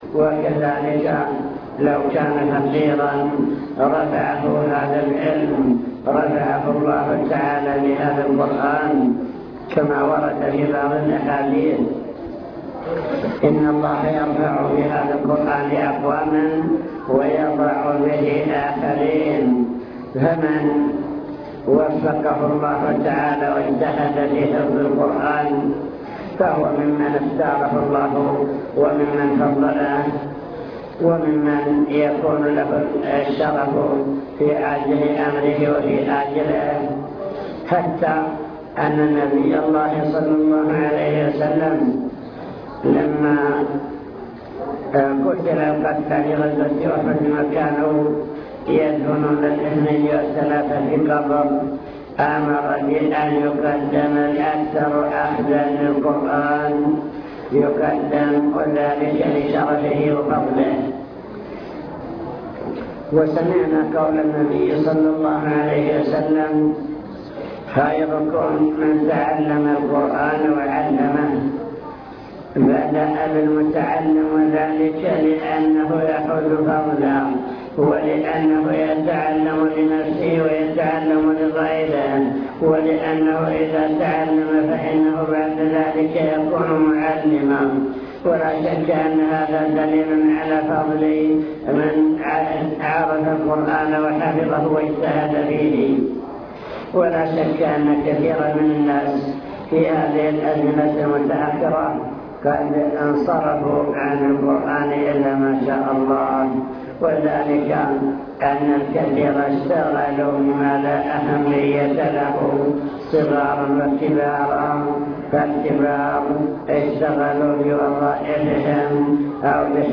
المكتبة الصوتية  تسجيلات - لقاءات  احتفال التحفيظ مع كلمة الشيخ فضل القرآن